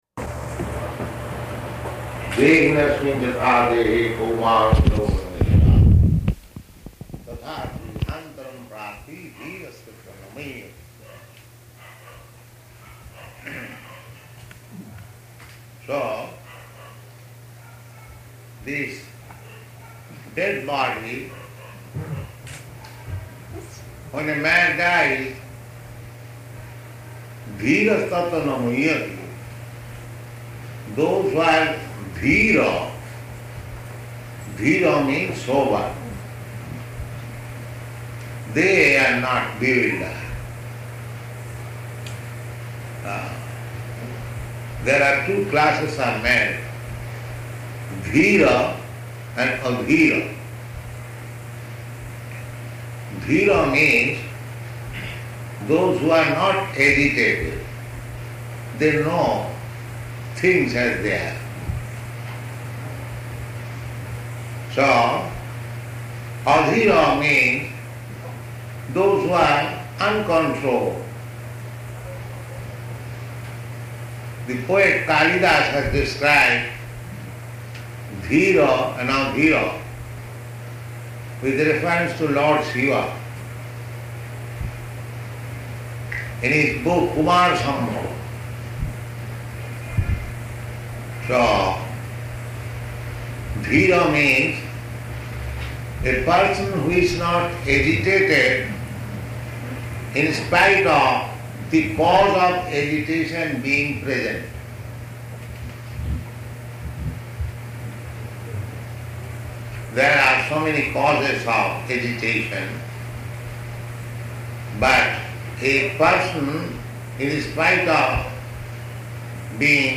Location: Manila